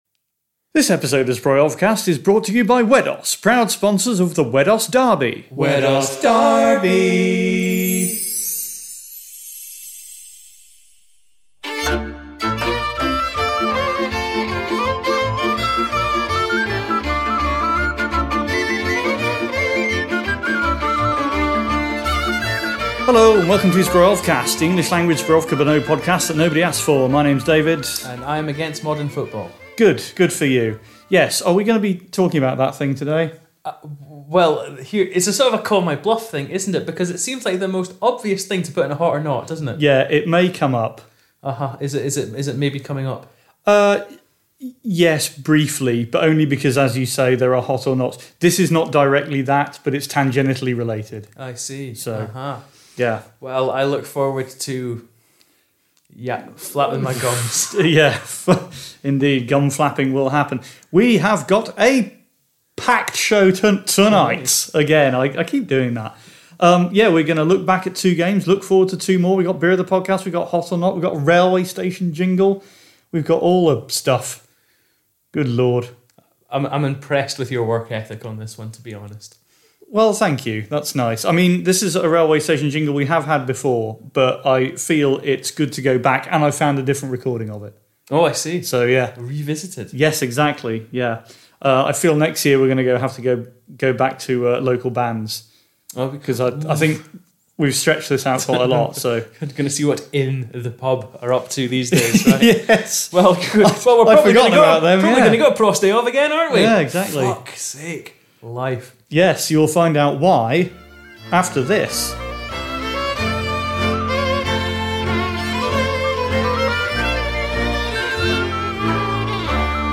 A fantastic episode featuring two games, a quiz, a station jingle, a sparkly sponsor, mail-order beer, shirt-based charity, some previews, bad Yiddish, a post-outro Easter egg and all the usual nonsense.